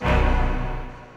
dre horn 1.wav